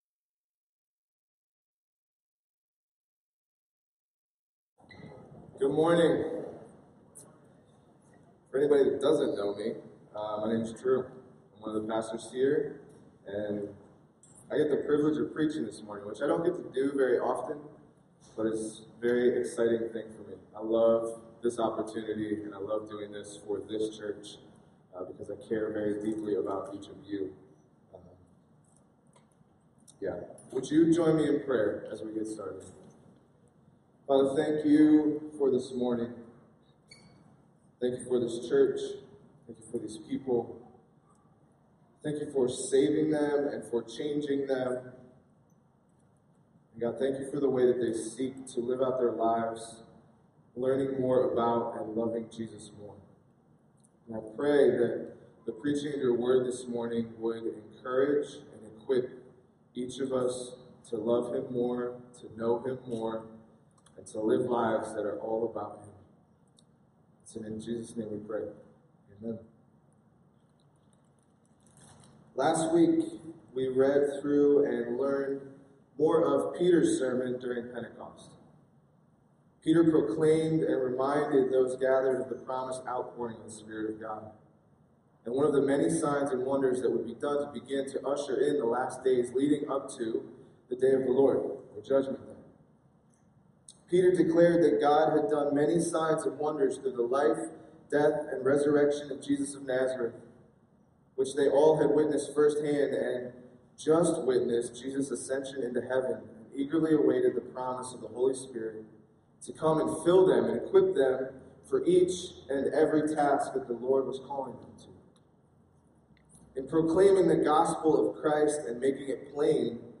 A sermon from the series "Stand Alone Sermons."